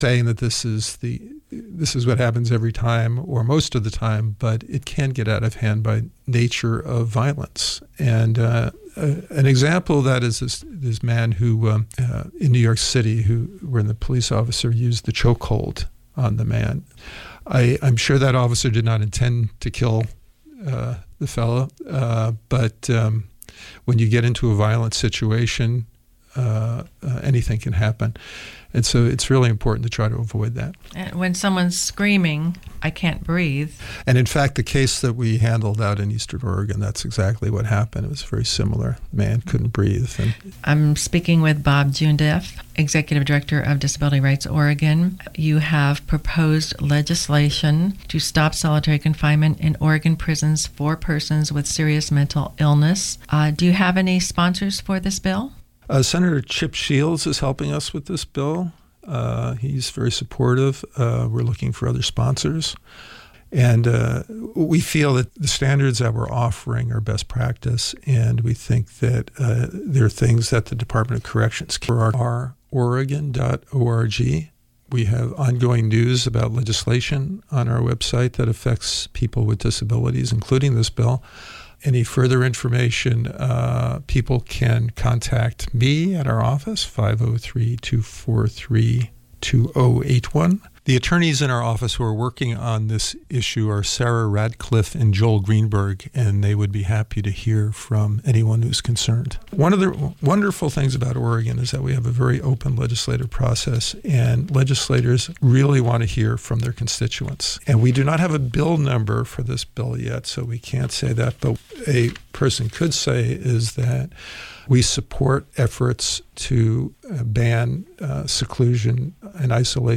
Sounds and stories from the deck of the UV746 Tugboat on the Willamette River.